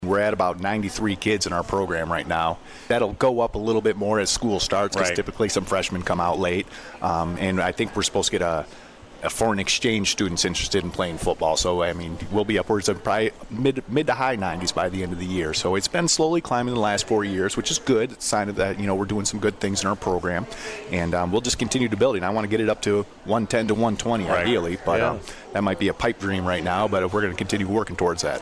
96.5 The Cave and Lenawee TV held their annual Football Coaches Preview Show Sunday at Hometown Pizza at the Lakes…in Manitou Beach.